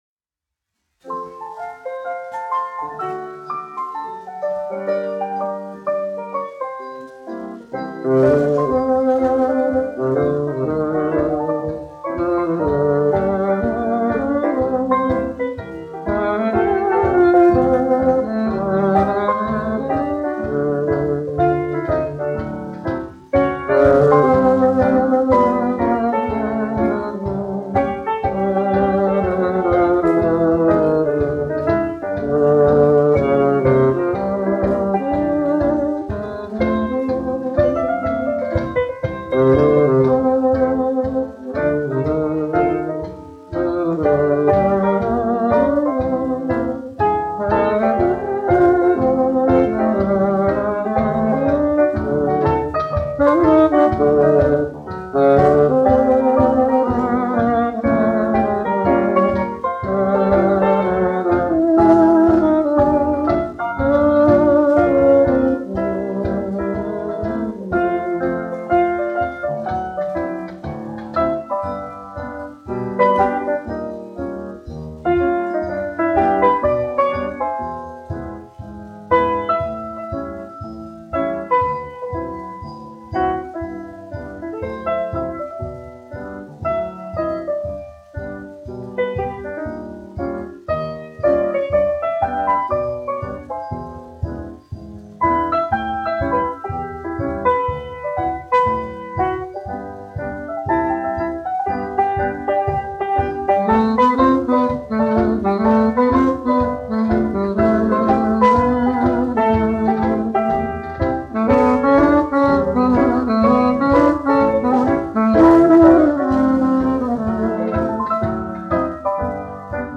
1 skpl. : analogs, 78 apgr/min, mono ; 25 cm
Populārā instrumentālā mūzika
Fokstroti
Skaņuplate